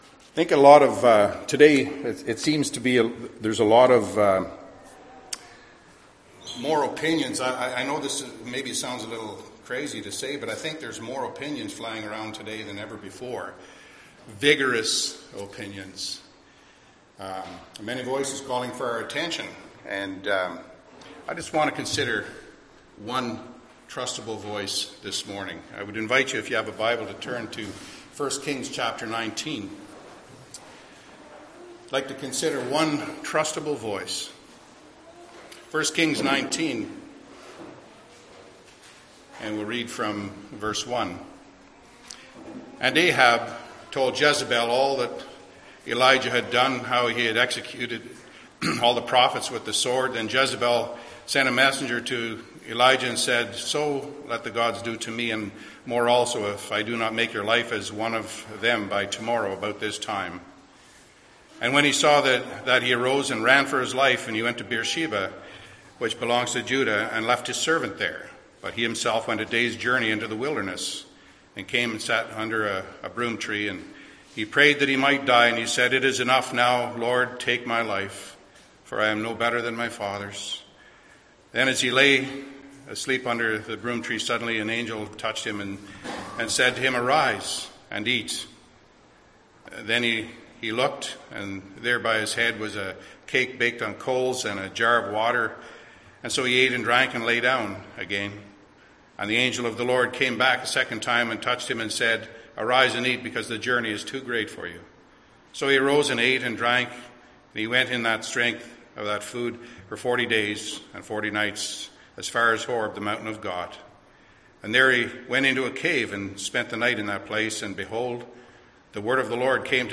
Passage: 1 Kings 19:1-18 Service Type: Sunday AM Topics